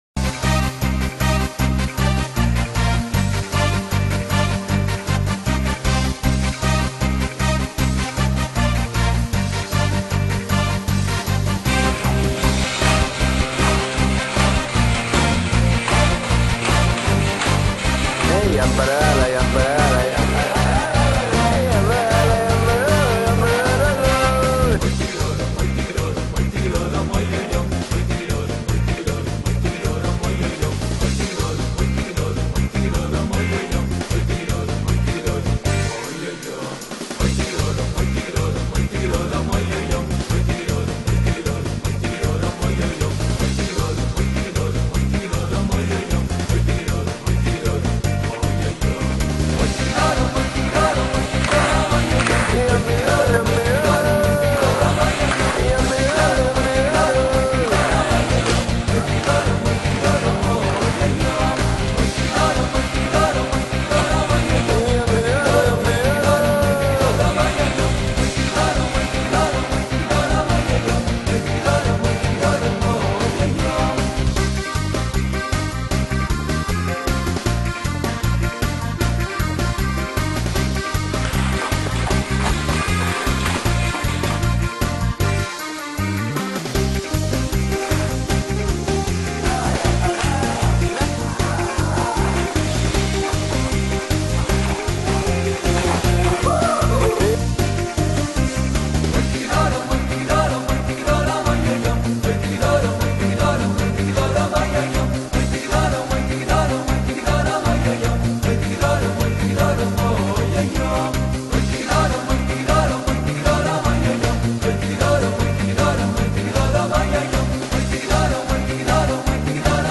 הניגון הבא כקובץ שמע
ניגון ים פללי לי- ניגון אוי דיגי דוי.mp3